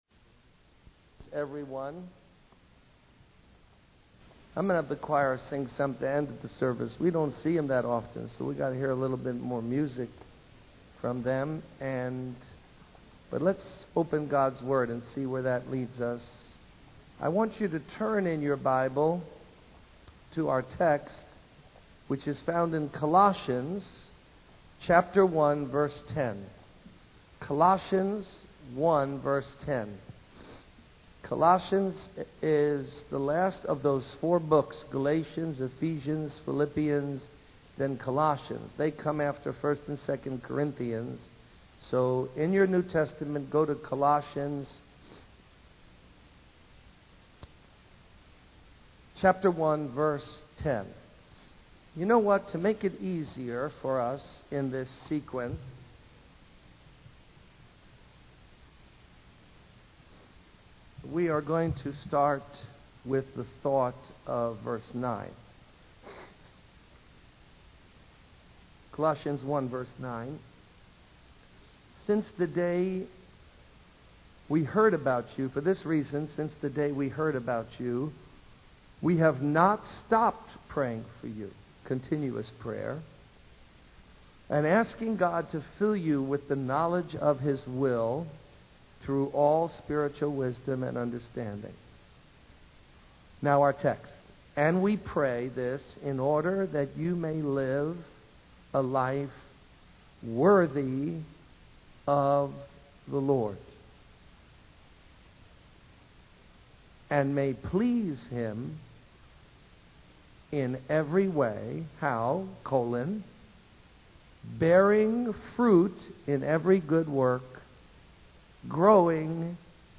In this sermon, the speaker emphasizes the importance of bearing fruit in the Christian life. They share a story about a woman who kept a secret and was consumed by guilt.